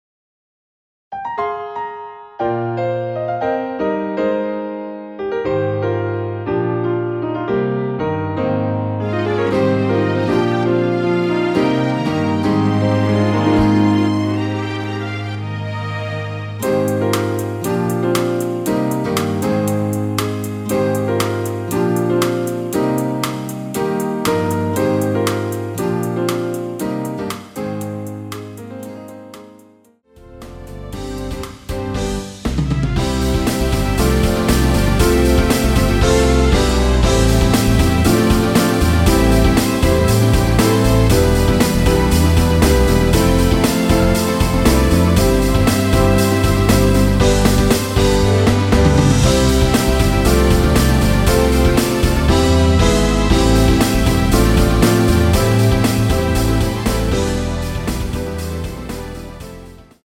Eb
노래방에서 음정올림 내림 누른 숫자와 같습니다.
앞부분30초, 뒷부분30초씩 편집해서 올려 드리고 있습니다.
중간에 음이 끈어지고 다시 나오는 이유는